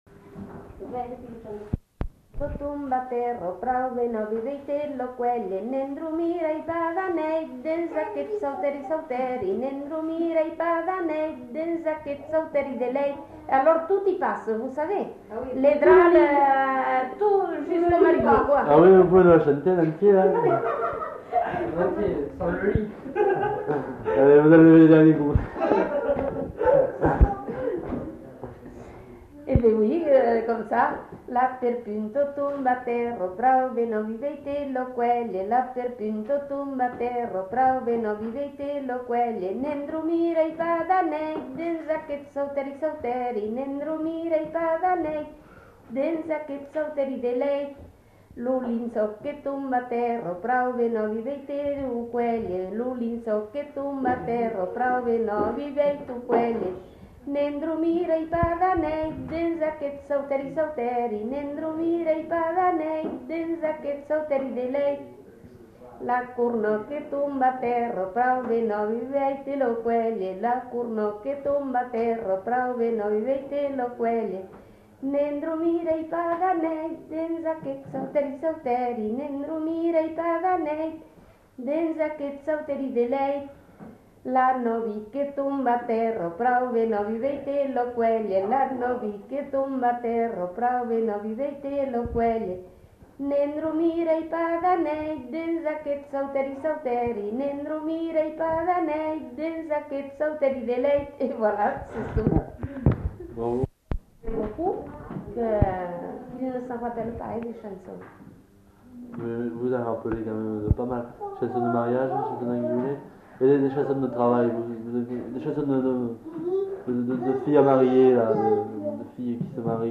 Chants de noces et airs à danser fredonnés
enquêtes sonores